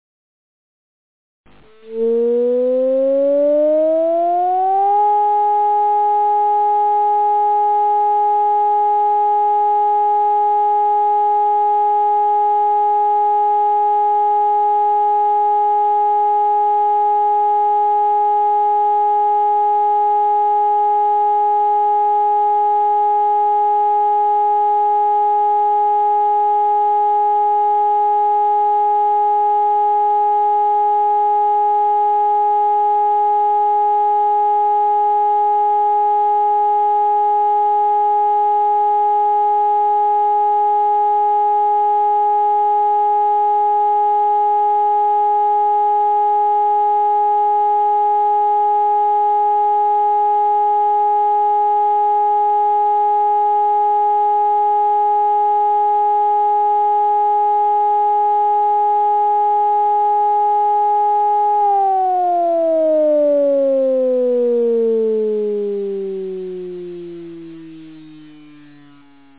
Akustische Signale für Warnung und Entwarnung
Entwarnung
entwarnung.mp3